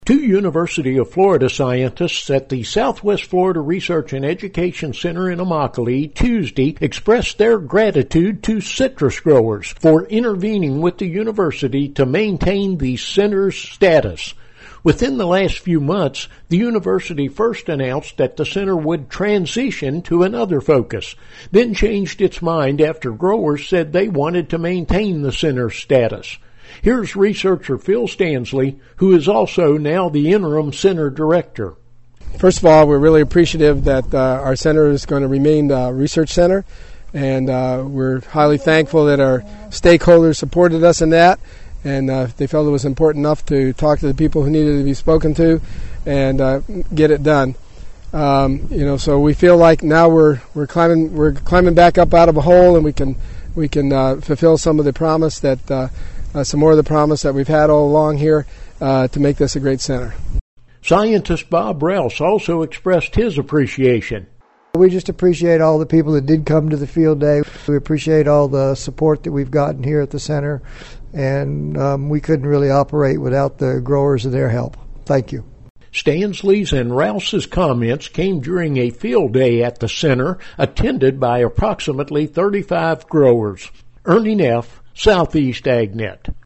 At a field day Tuesday, two University of Florida scientists expressed appreciation to citrus growers for intervening with the university to keep the Southwest Florida Research and Education Center operating under its current status.